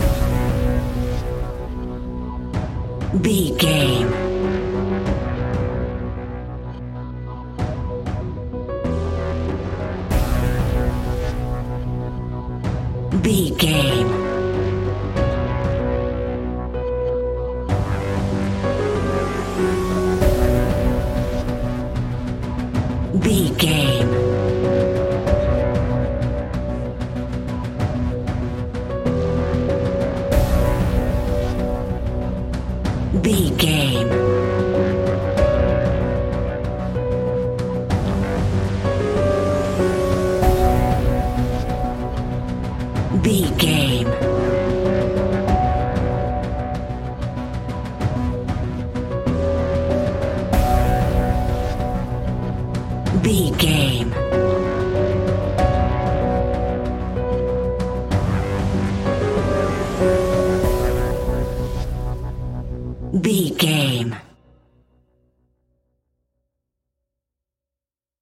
Aeolian/Minor
ominous
dark
eerie
drums
percussion
synthesiser
tense
instrumentals